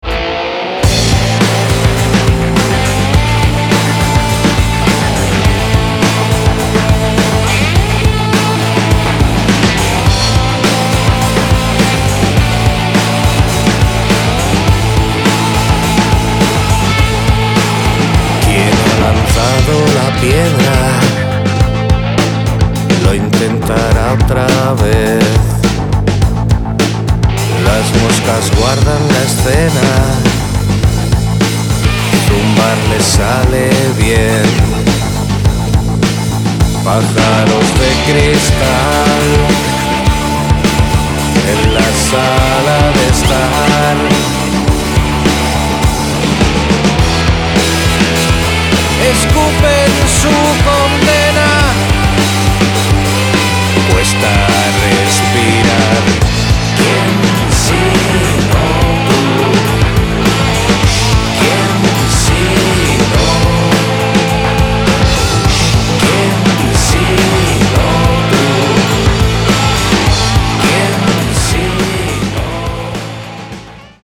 Pop/Rock